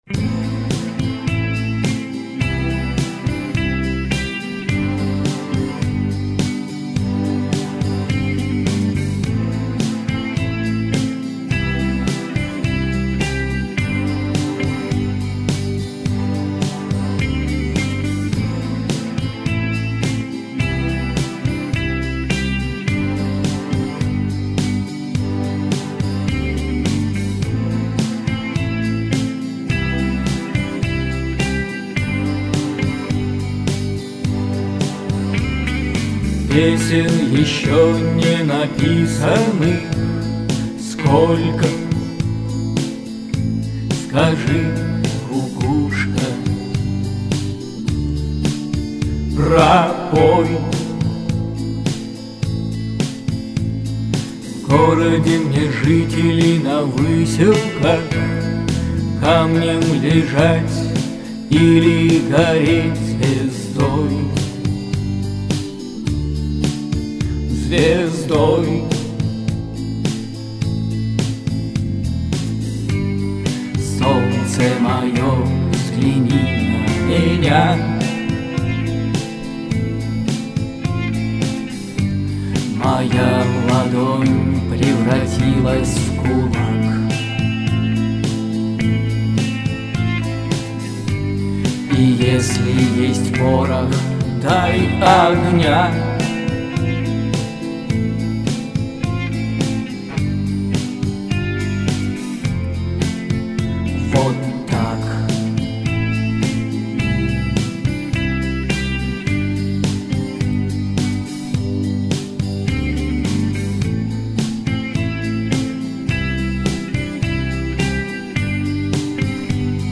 не хватило "напора", уверенности бы хотелось...